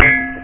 type_dink.wav